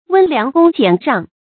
温良恭俭让 wēn liáng gōng jiǎn ràng
温良恭俭让发音